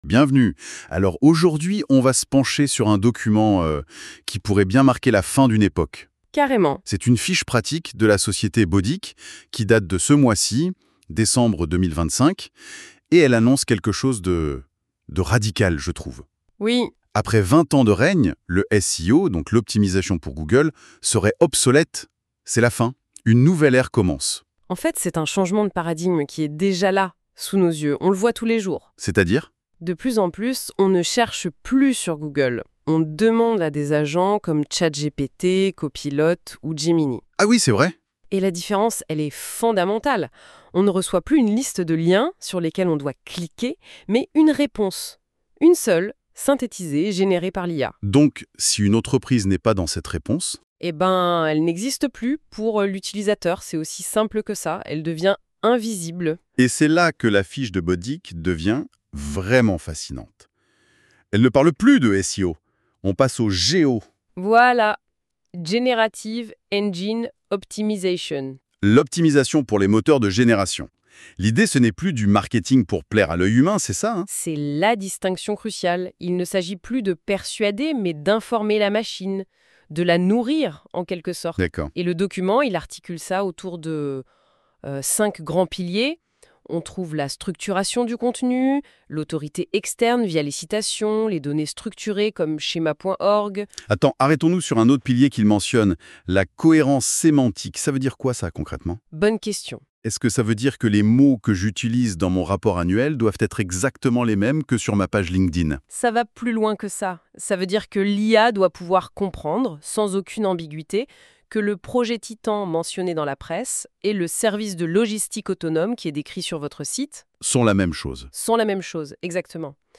NB : le podcast de BODIC est généré par l’outil d’IA NotebookLM à partir du contenu de cette fiche PDF écrite par l’équipe Bodic